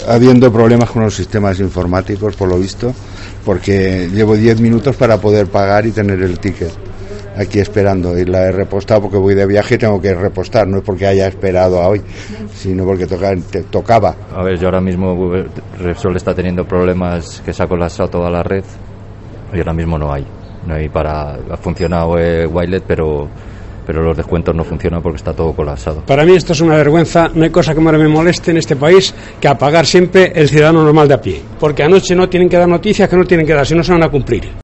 Tren voces clientes echando gasolina